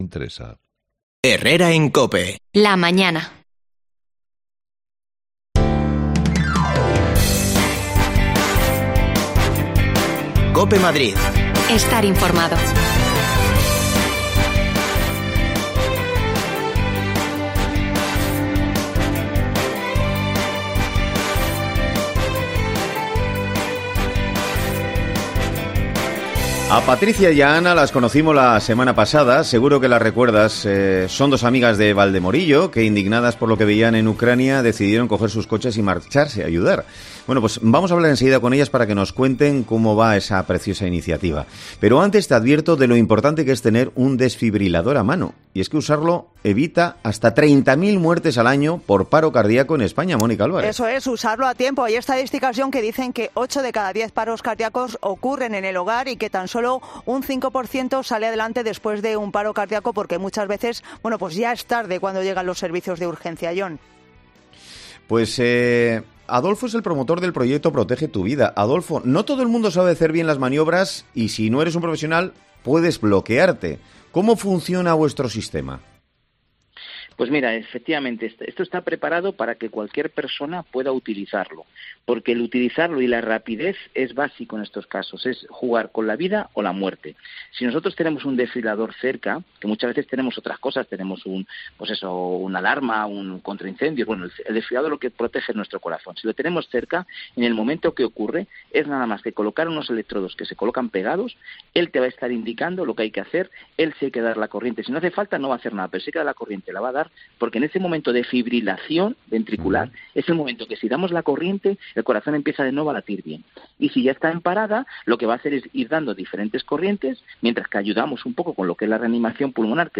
desconexiones locales de Madrid